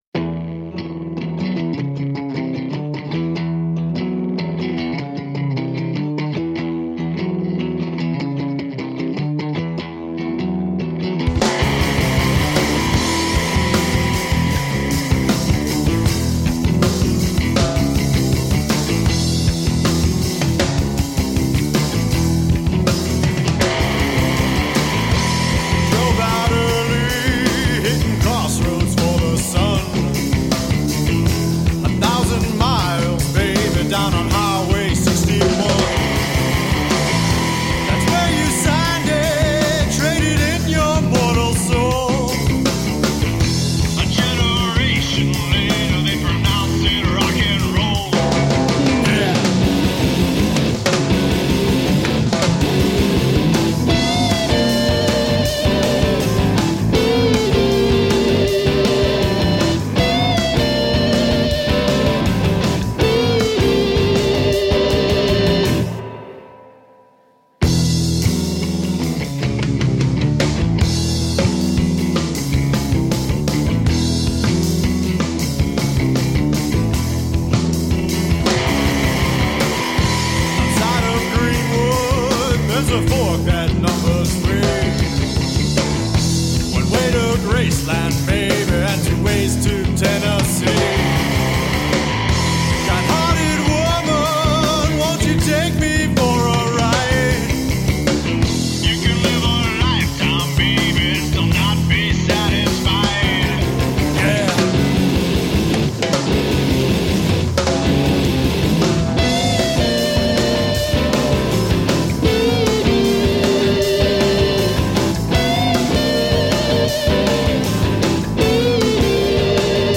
Dark and driving energetic alternative rock.
the angular and explosively blues-tinged
Vocals and Guitar
Bass Guitar
Drums
Tagged as: Alt Rock, Rock, Indie Rock